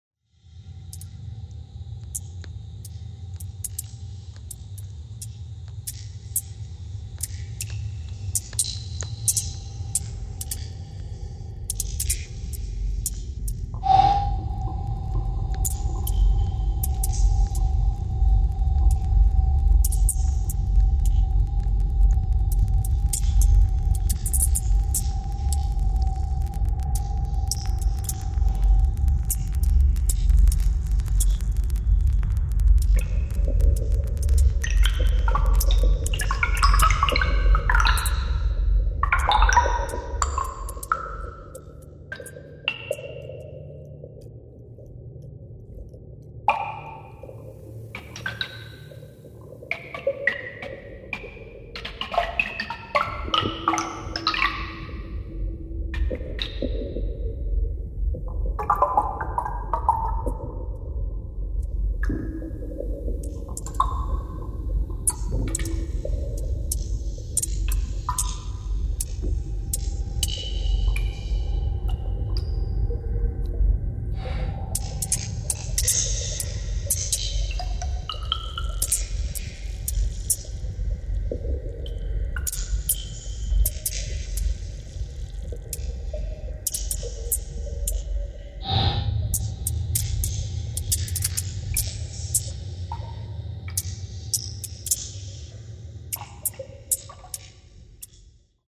In der Installation basieren alle Sounds auf Tönen aus der Umgebung des Kanderfirns, die mit herkömmlichen sowie Unterwasser- und Ambisonic- Mikrofonen aufgenommen wurden. Die Tonspur entwickelt sich von tieffrequenten Sounds aus Eishöhlen und Gletscherspalten zu immer abstrakteren Klanglandschaften.
In einer faszinierenden, eisfreien »neuen Welt« erinnern feine, abstrakte Klänge wie ein fernes Echo an den verschwundenen Gletscher.